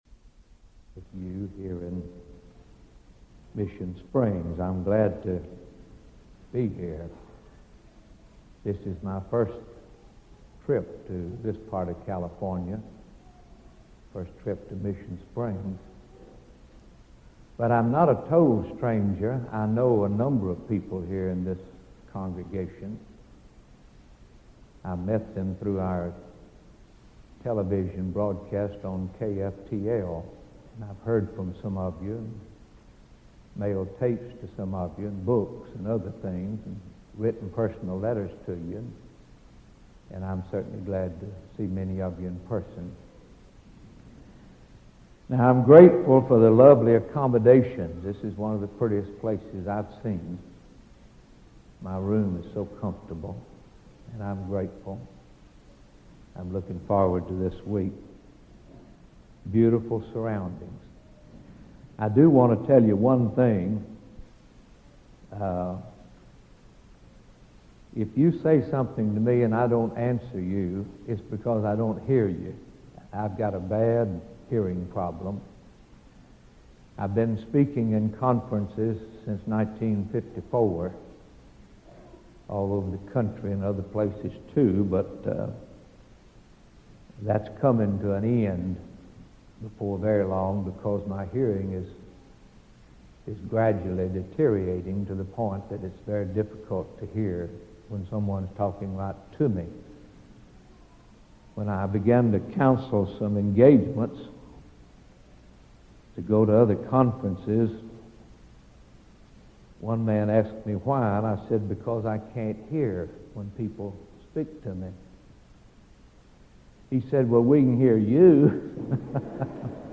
All Spiritual Blessings | SermonAudio Broadcaster is Live View the Live Stream Share this sermon Disabled by adblocker Copy URL Copied!